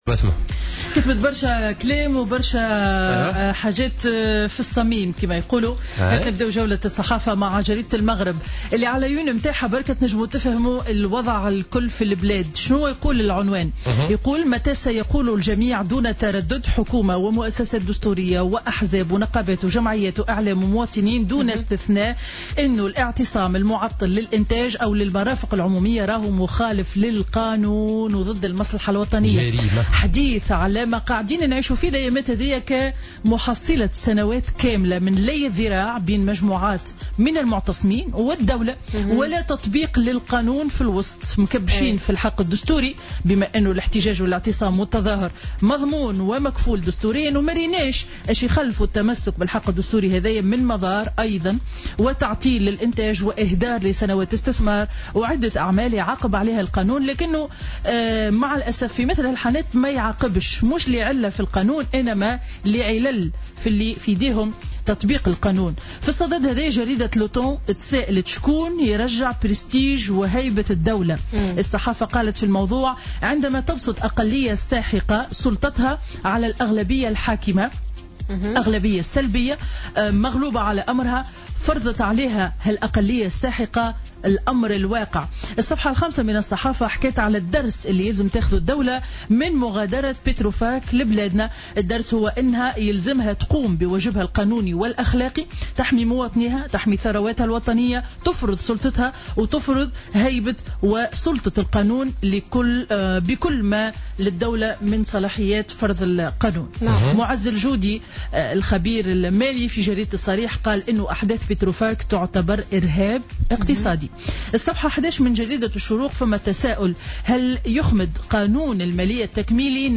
Revue de presse du jeudi 22 septembre 2016